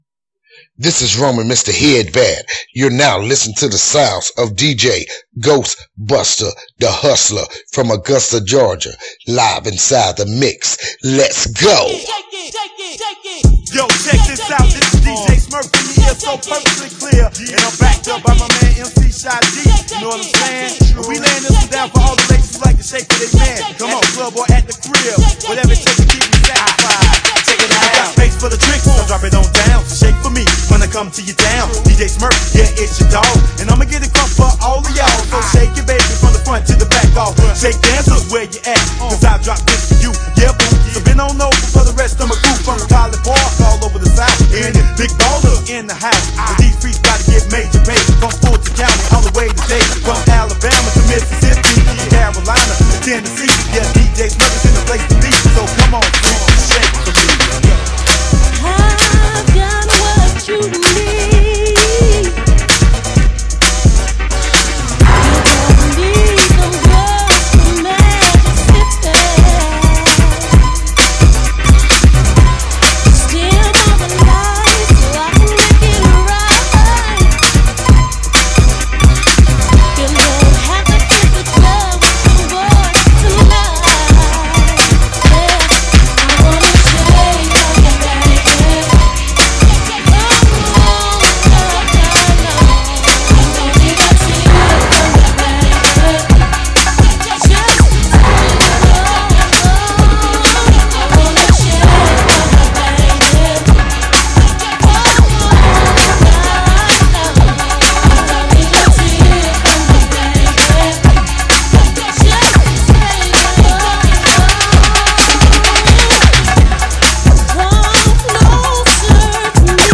Old School Hip-Hop
Blazing Booty Bass Classic's From The 80's & 90's